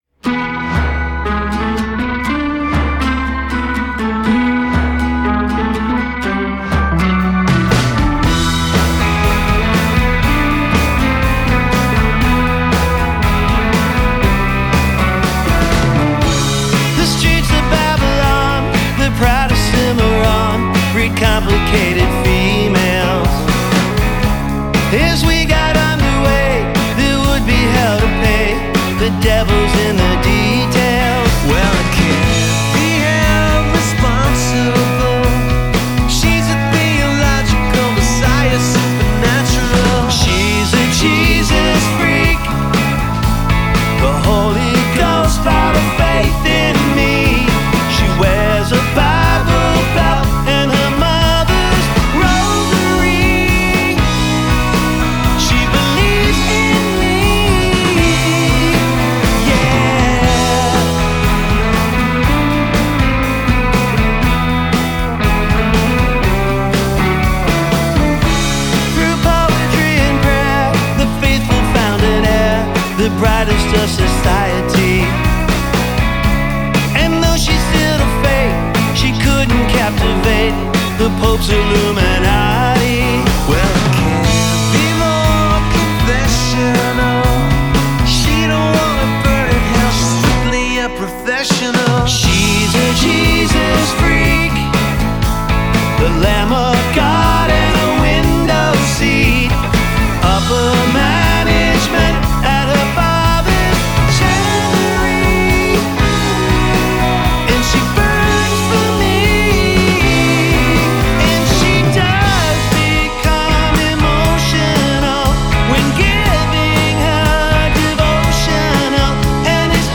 pop psychedelic